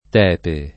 tepere [t$pere] v.; tepe [